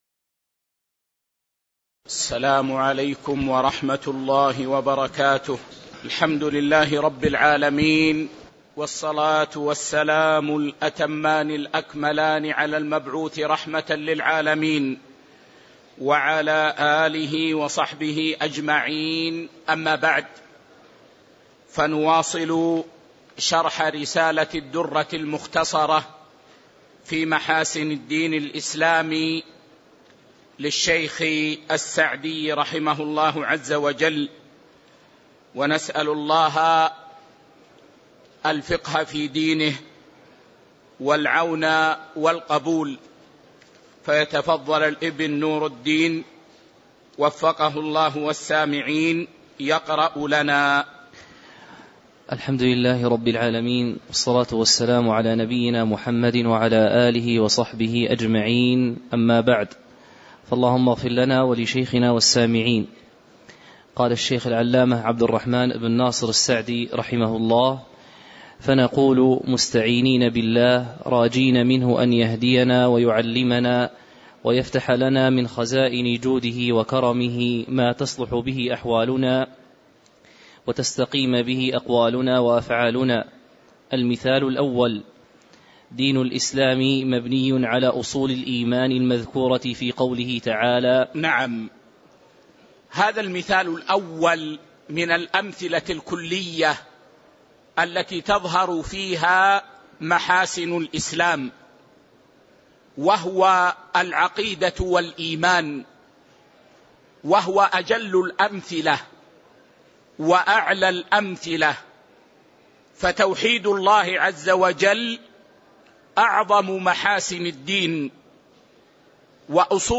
تاريخ النشر ١٧ شعبان ١٤٤٤ المكان: المسجد النبوي الشيخ